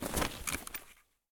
inv_close.ogg